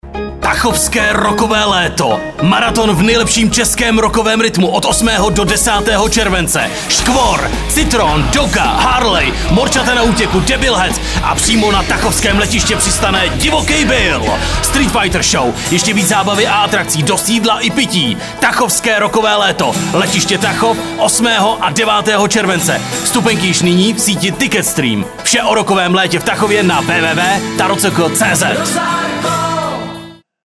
Zvuková upoutávka.